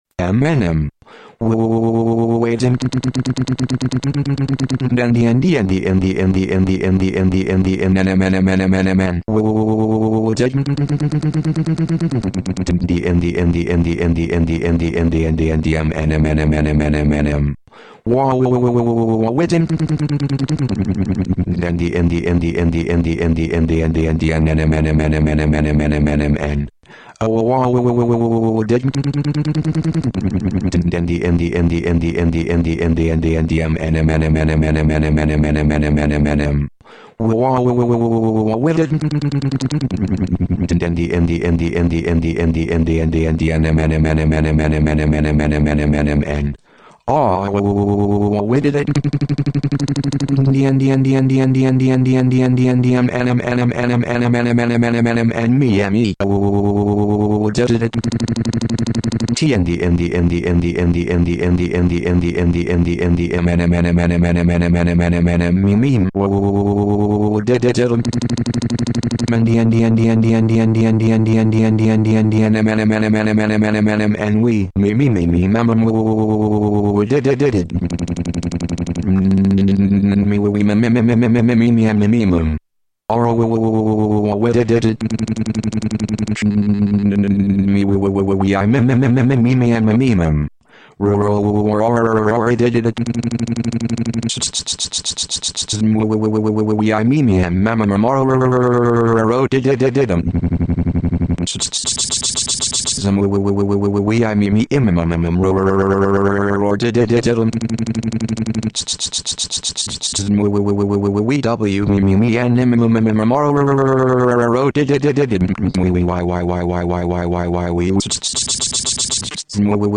Dramatic reading